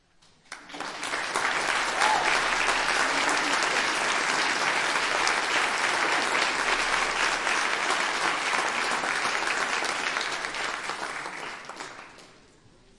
掌声 " 掌声 5 教堂立体声
描述：大约200人在教堂里鼓掌。用Zoom Q3HD录制。
标签： 教堂 观众 欢呼 鼓掌 人群 掌声
声道立体声